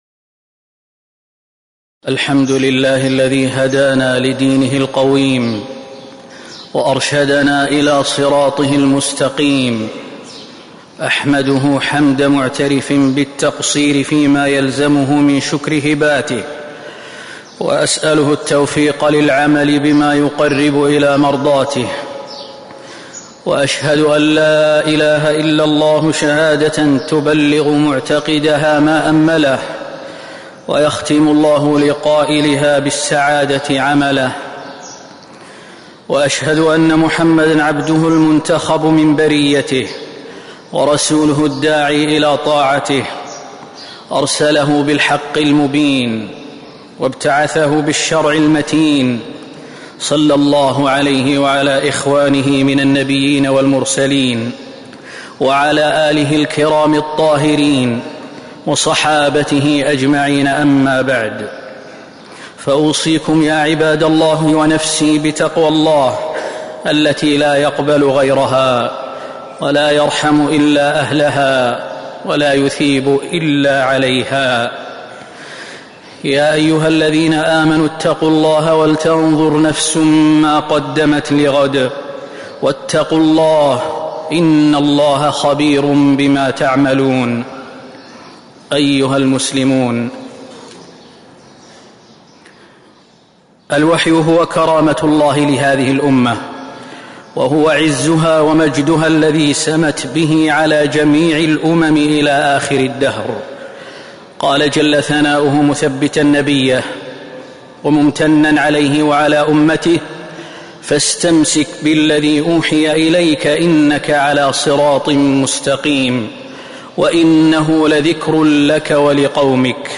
تاريخ النشر ٢٦ جمادى الآخرة ١٤٤٦ هـ المكان: المسجد النبوي الشيخ: فضيلة الشيخ د. خالد بن سليمان المهنا فضيلة الشيخ د. خالد بن سليمان المهنا الوحي وفضلة